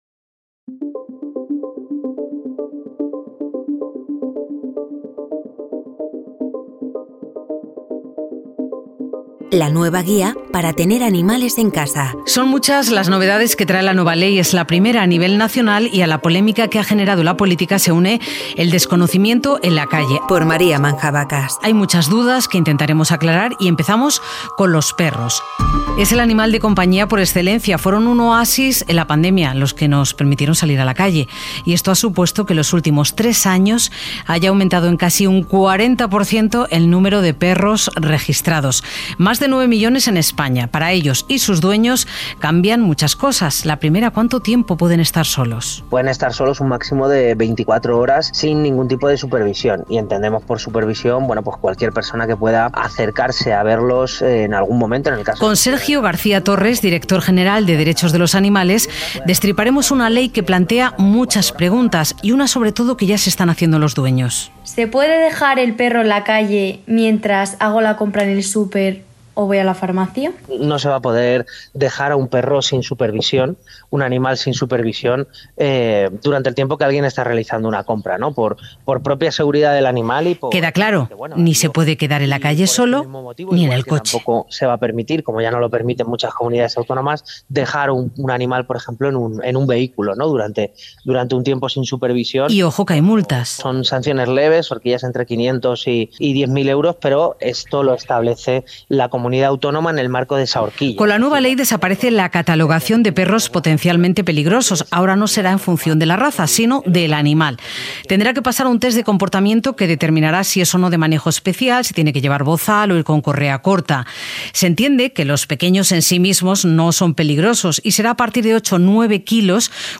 Reportaje EP65 | La nueva guía para tener animales en casa